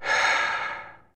sigh4.wav